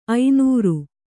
♪ ainūru